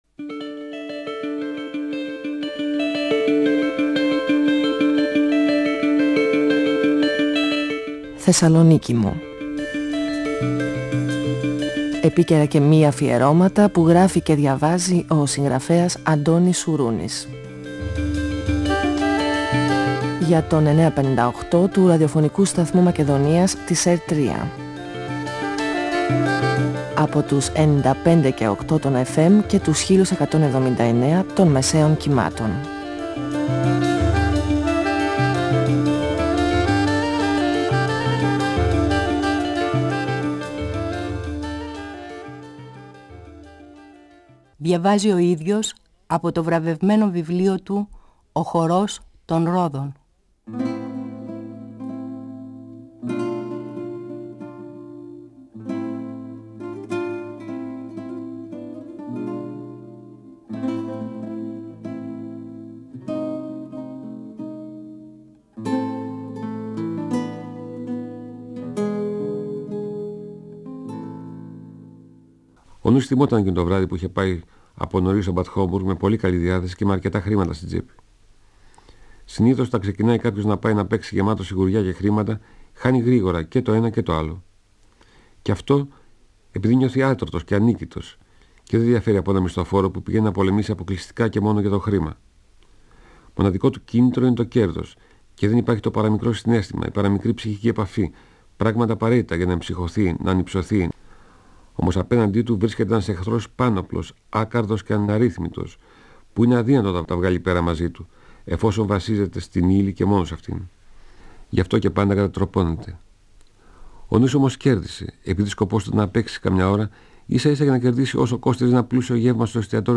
Ο συγγραφέας Αντώνης Σουρούνης (1942-2016) διαβάζει από το βιβλίο του «Ο χορός των ρόδων», εκδ. Καστανιώτη, 1994. Ο Νούσης θυμάται τις στιγμές που έζησε με τη Μόρφω και τα επεισόδια στο καζίνο.